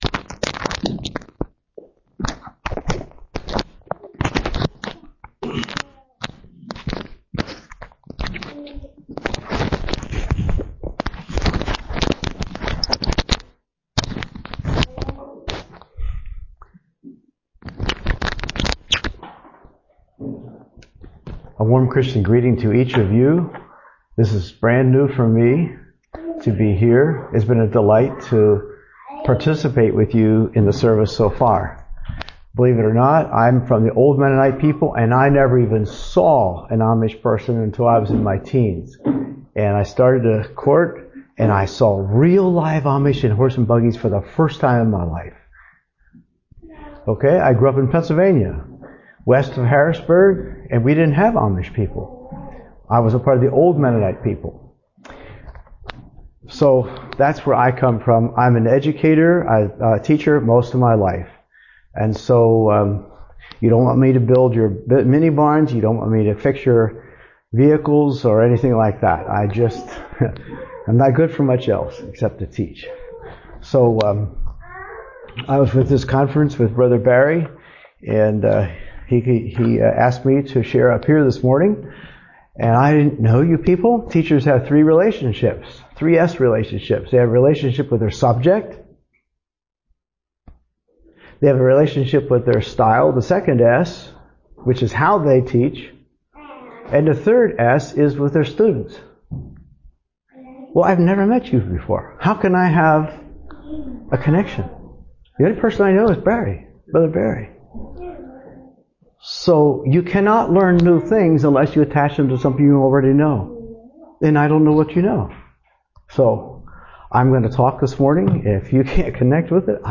Message
A message from the series "2025 Messages."